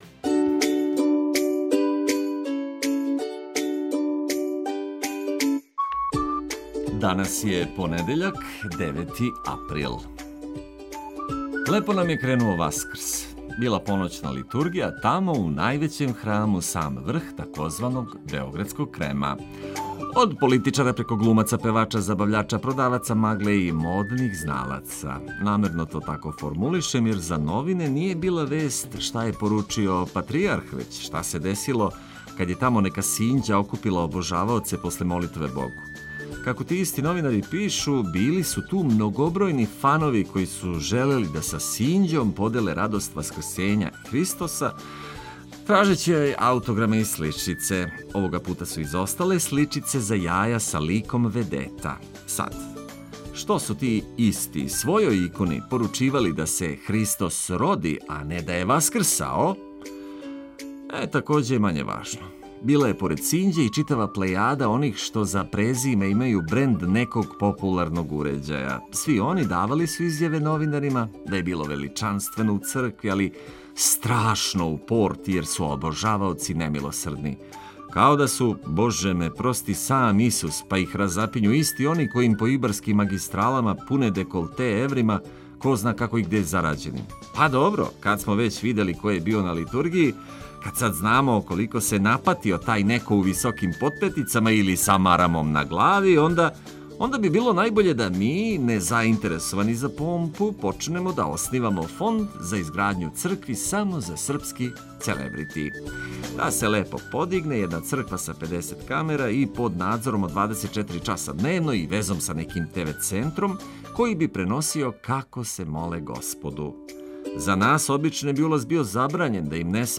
Наша препорука за ово априлско јутро је да се разбудите у нашем друштву, добро расположени, уз ведрину, музику и корисне инфромације, за почетак другог дана Васкрса који је за већину нерадан дан у Србији.